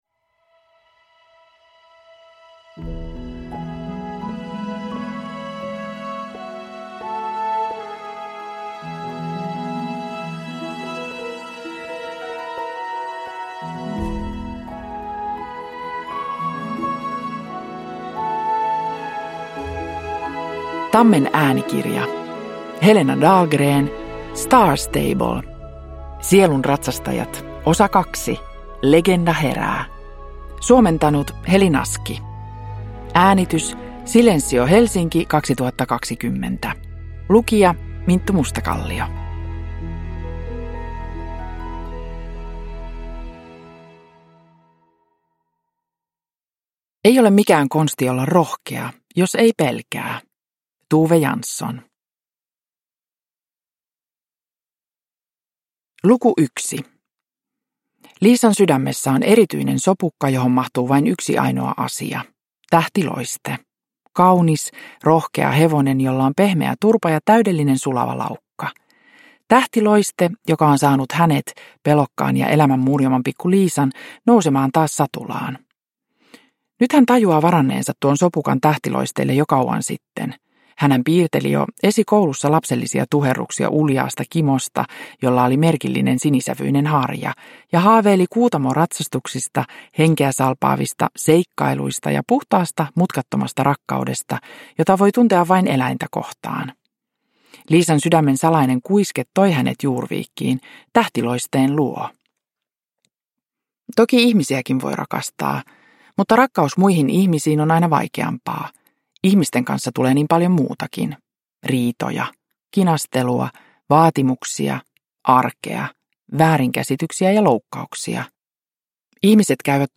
Star Stable. Sielunratsastajat #2: Legenda herää – Ljudbok – Laddas ner
Uppläsare: Minttu Mustakallio